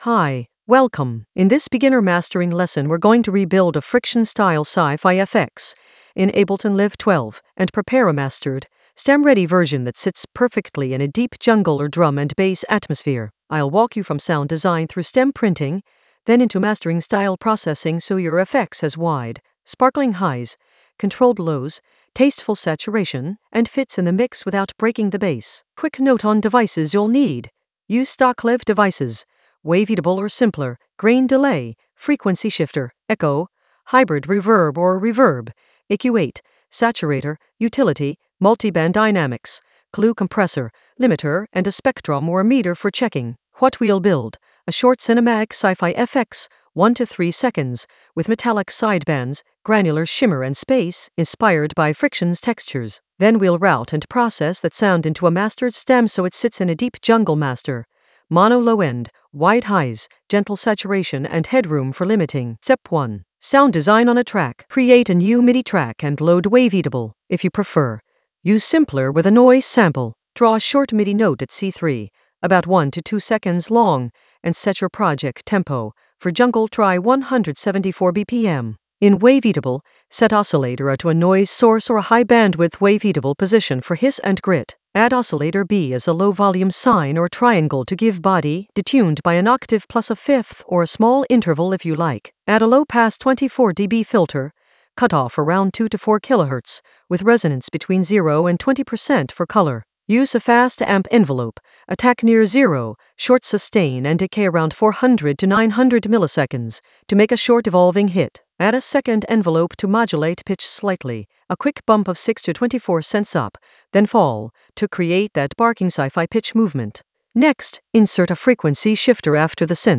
An AI-generated beginner Ableton lesson focused on Rebuild a Friction sci-fi FX in Ableton Live 12 for deep jungle atmosphere in the Mastering area of drum and bass production.
Narrated lesson audio
The voice track includes the tutorial plus extra teacher commentary.